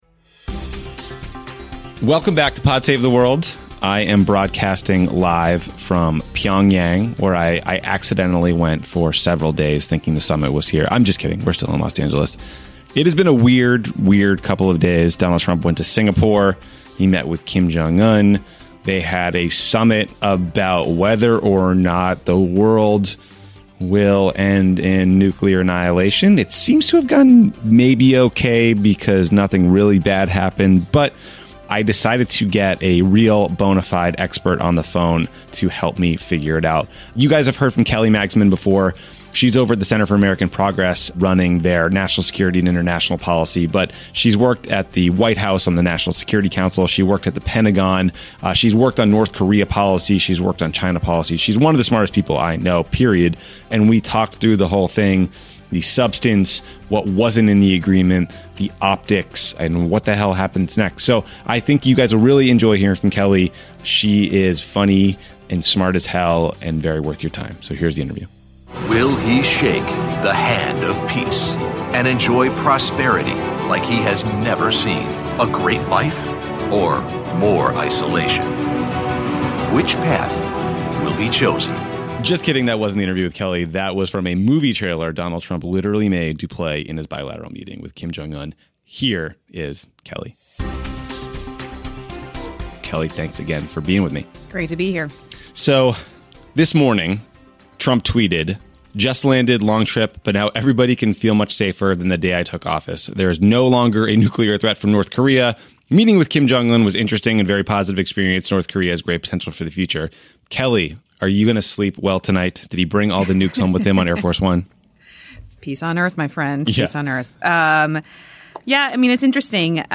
Tommy talks with defense and Asia policy expert Kelly Magsamen about Trump's just-completed summit with North Korea's Kim Jong-un. What did they accomplish? Are we safer? Should we worry about the optics of meeting with a dictator?